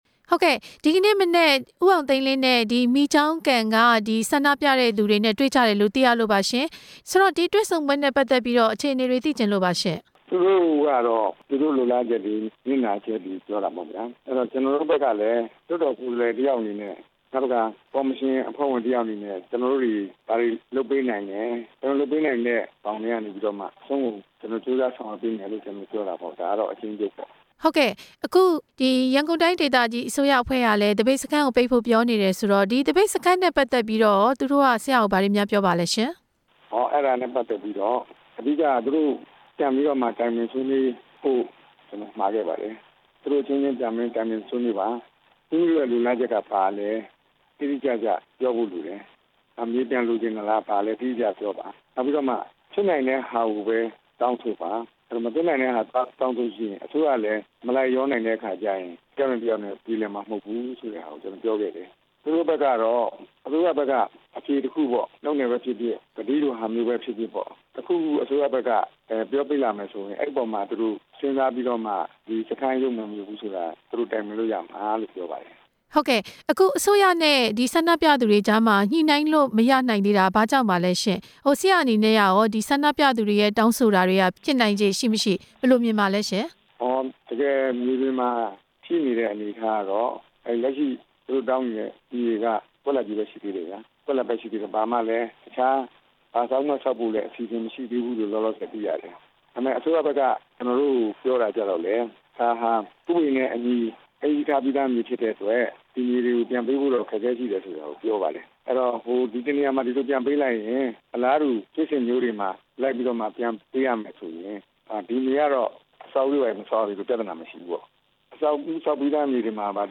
ဦးအောင်သိန်လင်းနဲ့ မေးမြန်းချက်